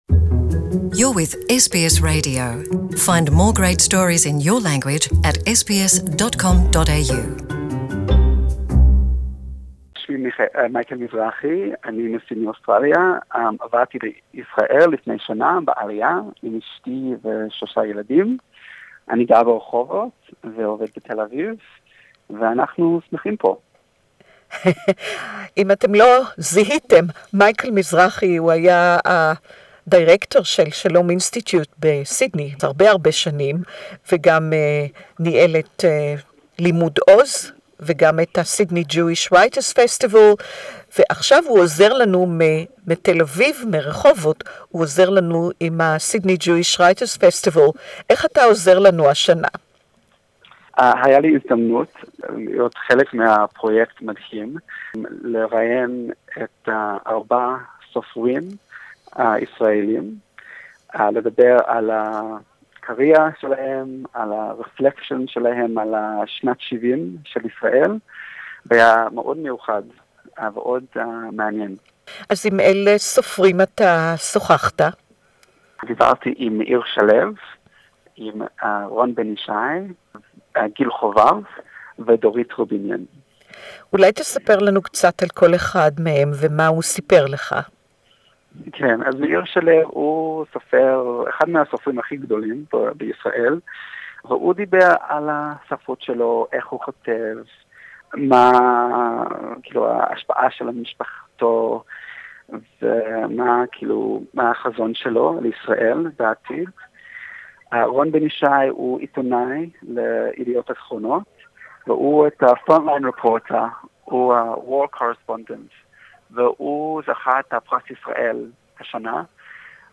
(Hebrew interview)